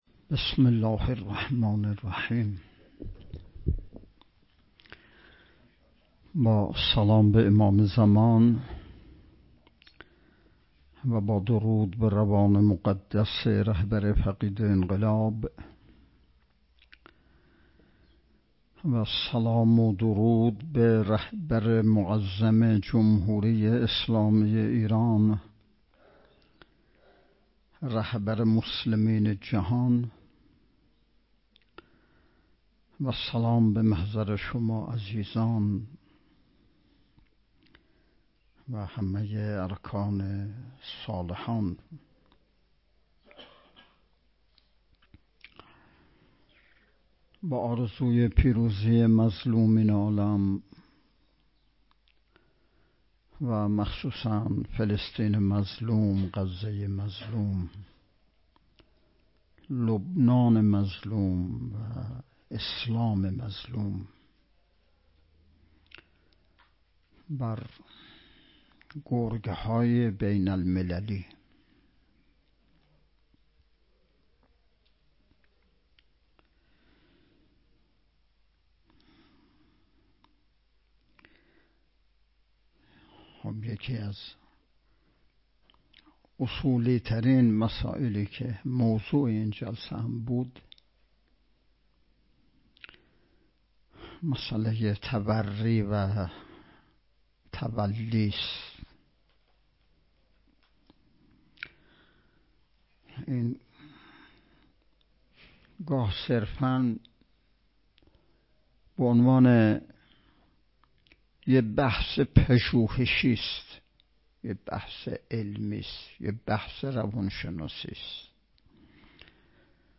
هفدهمین نشست ارکان شبکه تربیتی صالحین بسیج با موضوع تربیت جوان مؤمن انقلابی پای کار، صبح امروز ( ۲۱ فروردین) با حضور و سخنرانی نماینده ولی فقیه در استان، برگزار شد.